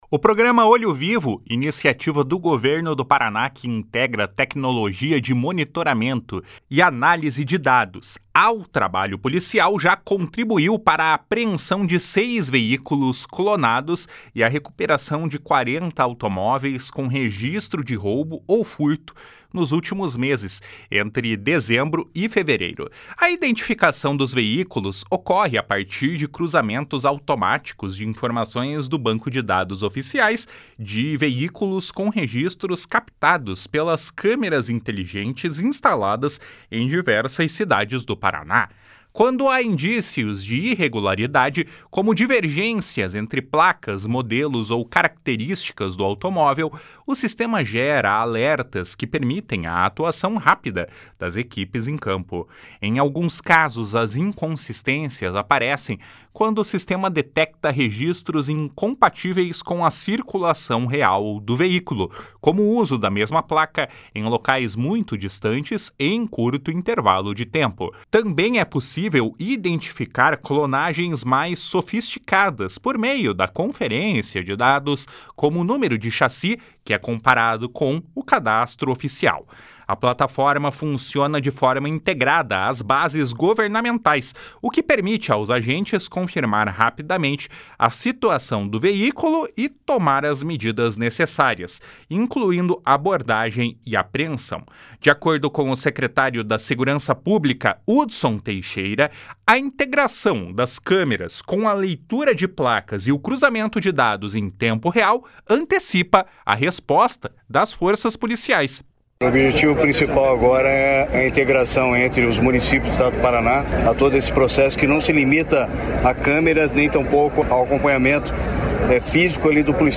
// SONORA HUDSON TEIXEIRA //